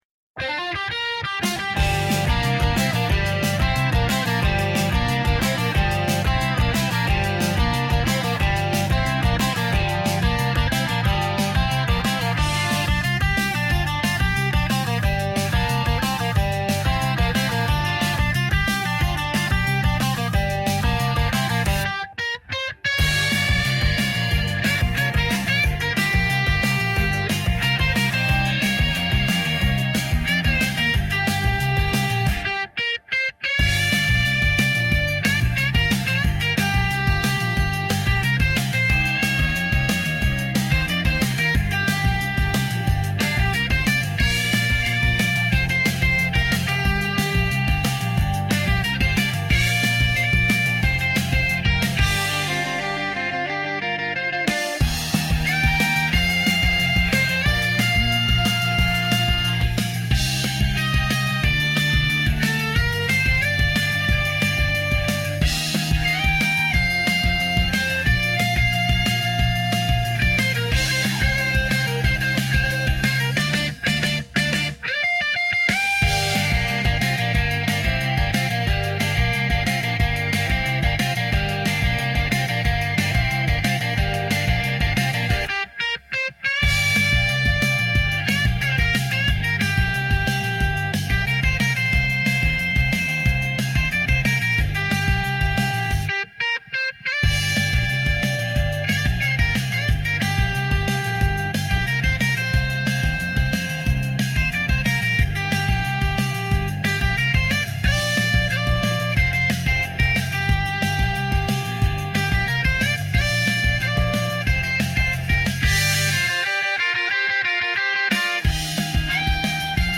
エレキギターがバイク乗ってうなっているイメージだったので…